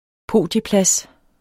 Udtale [ ˈpoˀdjə- ]